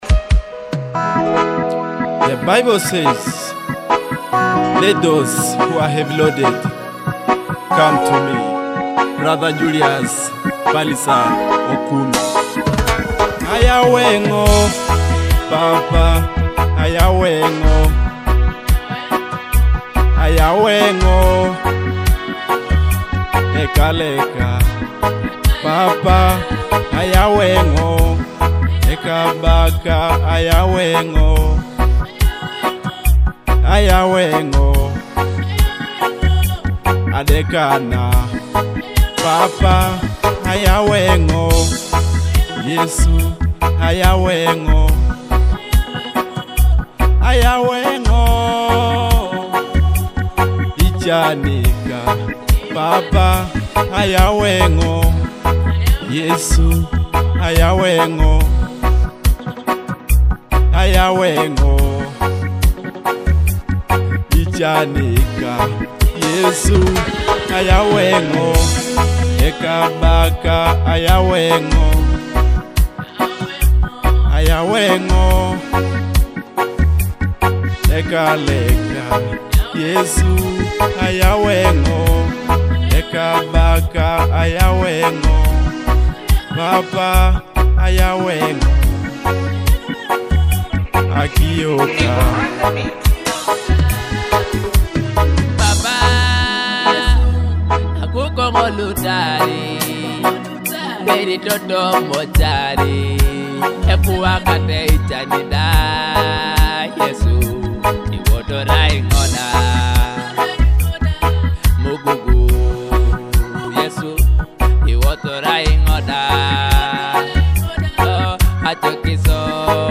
powerful gospel worship song